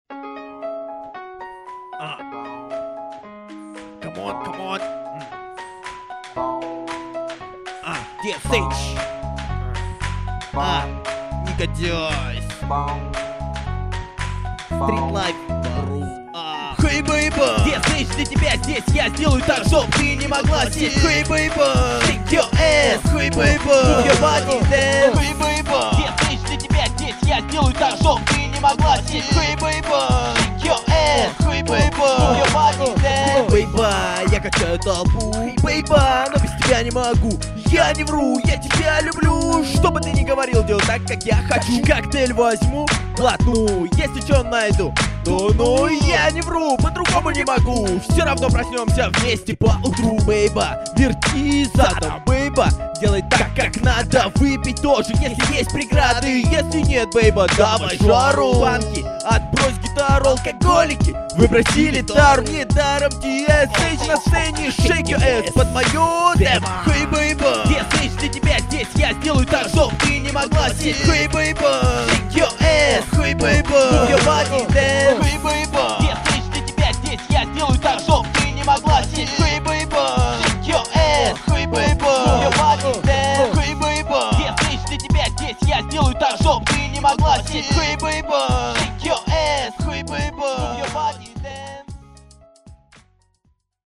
Рэп (46704)